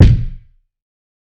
Kicks
TC3Kick4.wav